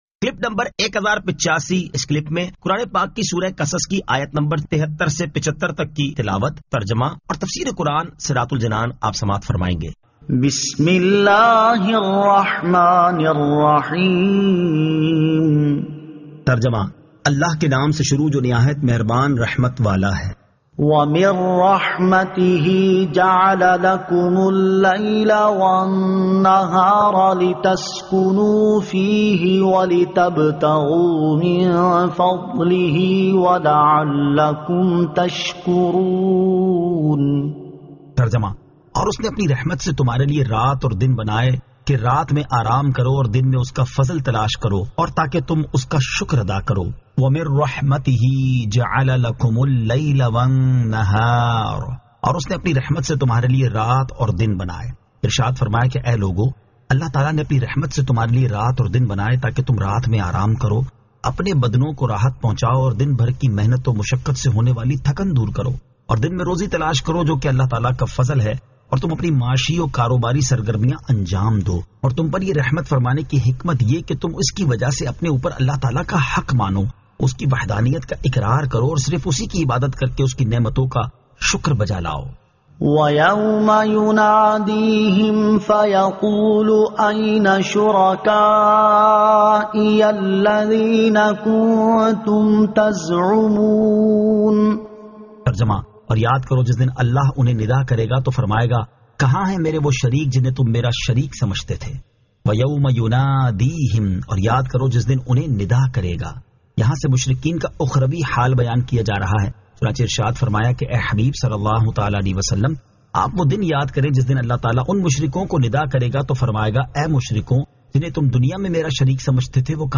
Surah Al-Qasas 73 To 75 Tilawat , Tarjama , Tafseer